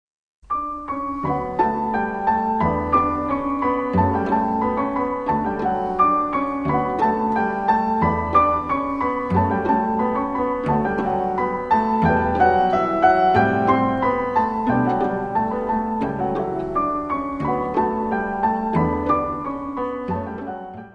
Área:  Música Clássica
Quintette nº1 pour piano et cordes in C minor.